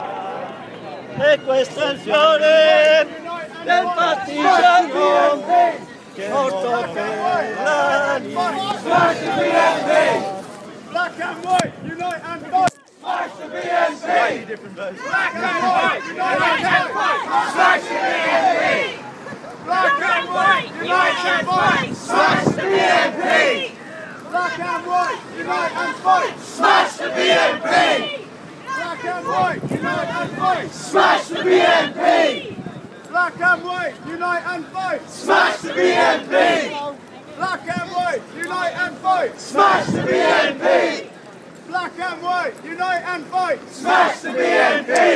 Chants from the march anti racist